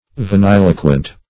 Vaniloquent \Va*nil"o*quent\, a. Talking foolishly.
vaniloquent.mp3